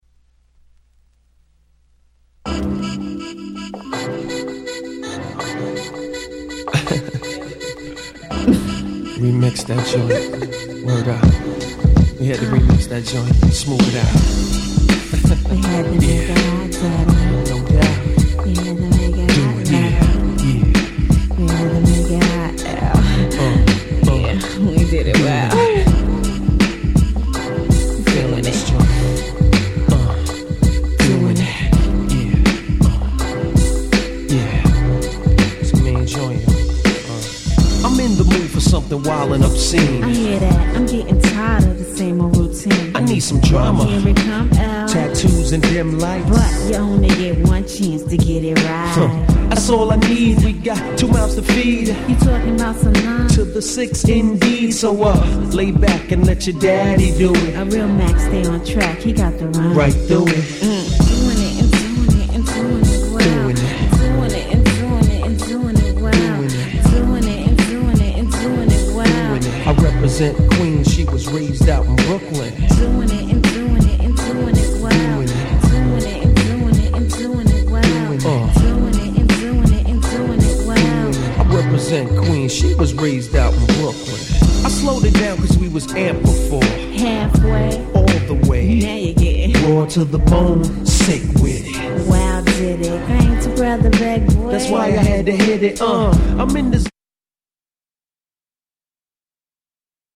オリジナルのエロエロな感じも捨て難いですが、Mixなんかで渋く使うには絶対にこっちのRemixでしょう！！